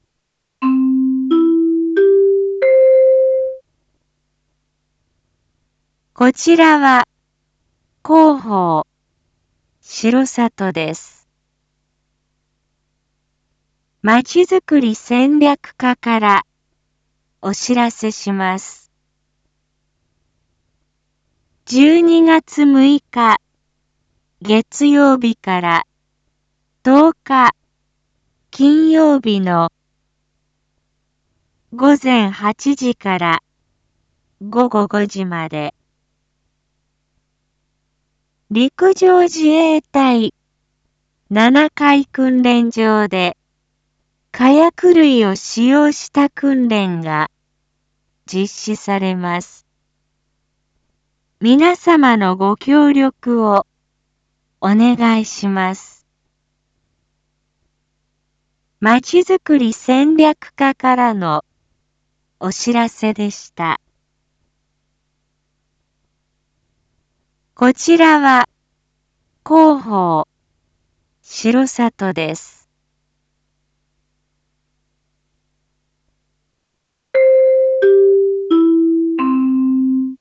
一般放送情報
Back Home 一般放送情報 音声放送 再生 一般放送情報 登録日時：2021-12-06 07:01:21 タイトル：R3.12.6 7時放送 インフォメーション：こちらは広報しろさとです。